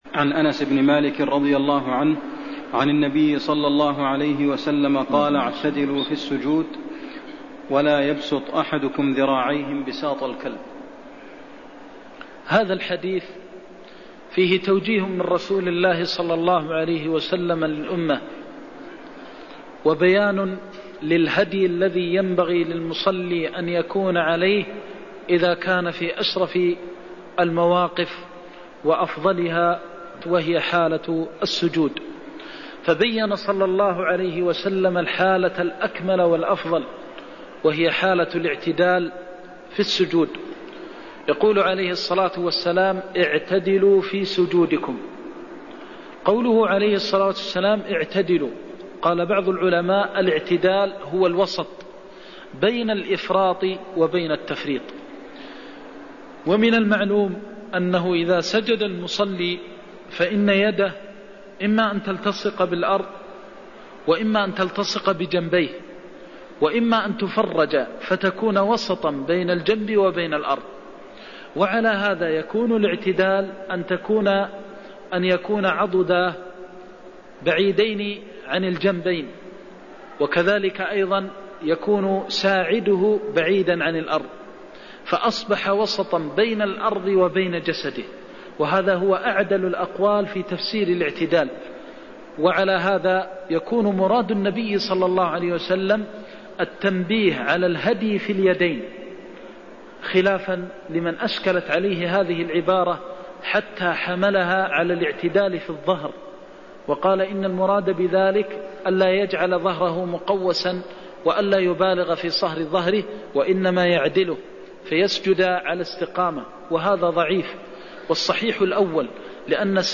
المكان: المسجد النبوي الشيخ: فضيلة الشيخ د. محمد بن محمد المختار فضيلة الشيخ د. محمد بن محمد المختار اعتدلوا في السجود ولا ينبسط أحدكم انبساط الكلب (91)